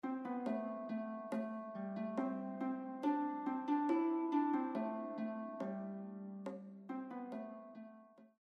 traditional Irish song
danced as a “jig.”